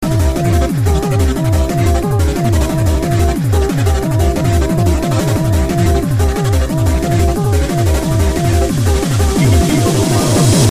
Drum'n Bassっぽい